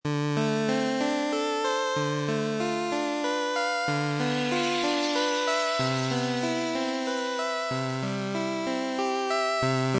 arpegio01-loop-45094.mp3